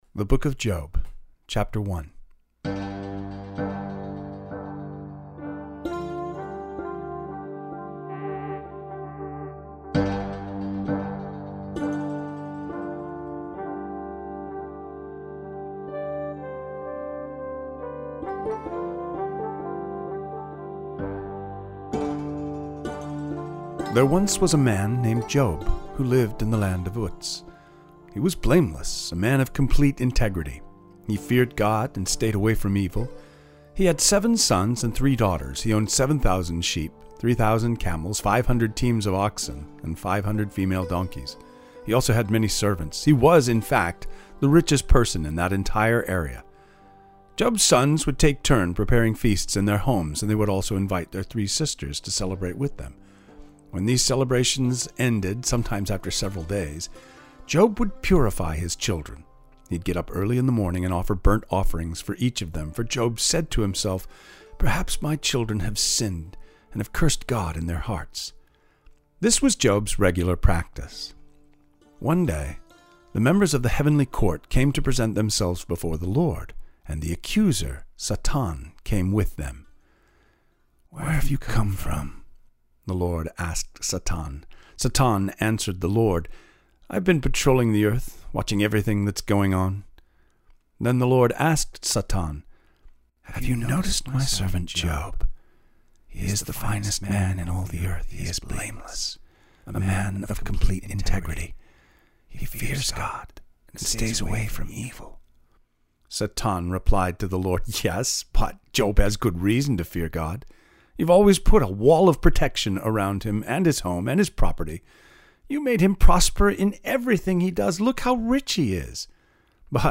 Read the first two chapters – or listen to the audio above.